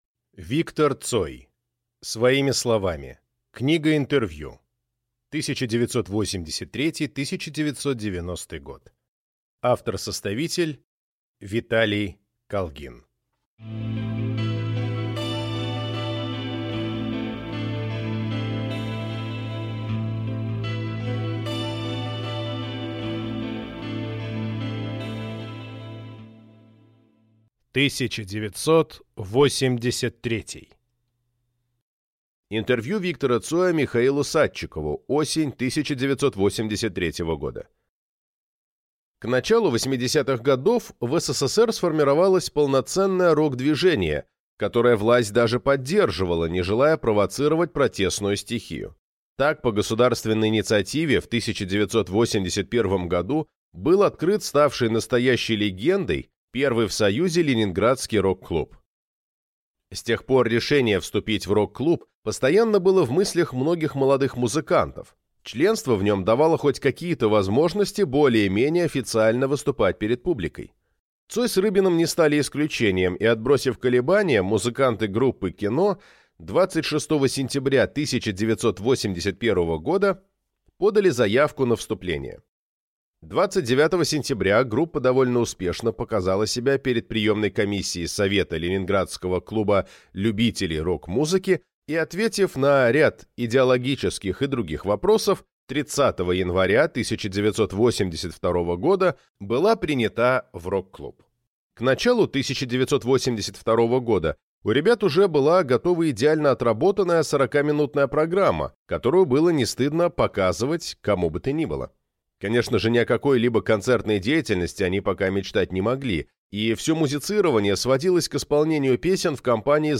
Аудиокнига Виктор Цой. Своими словами. Книга интервью. 1983–1990 | Библиотека аудиокниг